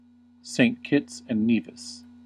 Saint Kitts and Nevis (/-ˈkɪts ...ˈnvɪs/
En-us-Saint_Kitts_and_Nevis.ogg.mp3